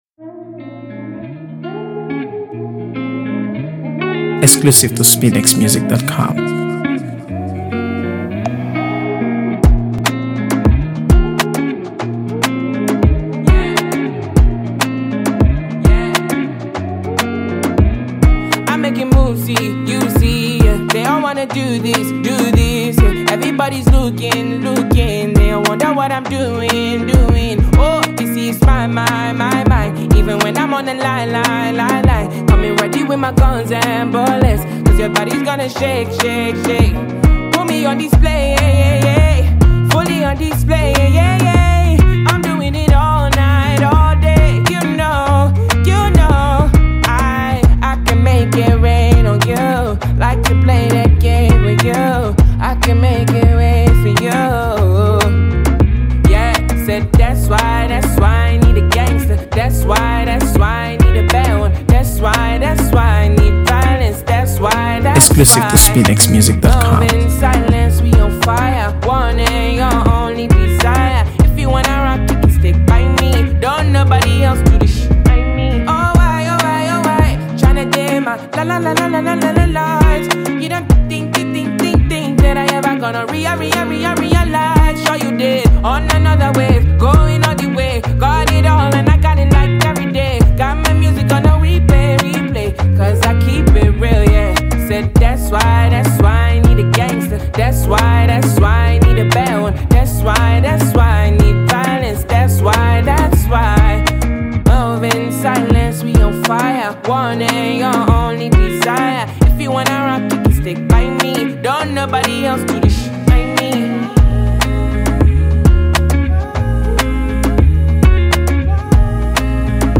AfroBeats | AfroBeats songs
captivating melodies
powerful vocals